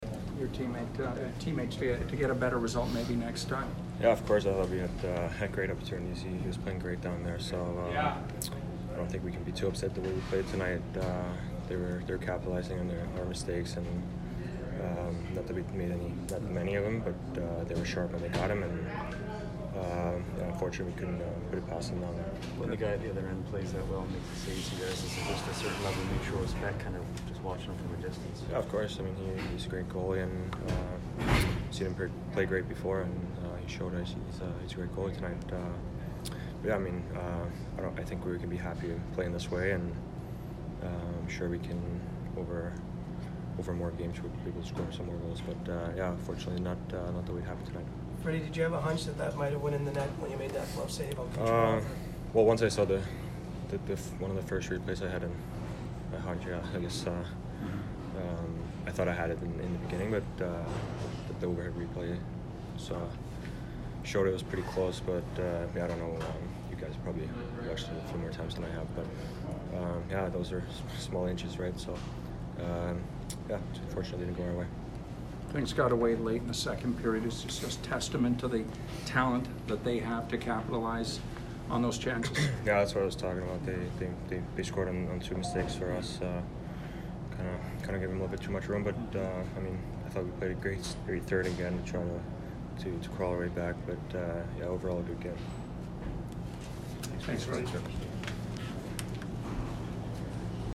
Frederik Andersen post-game 12/13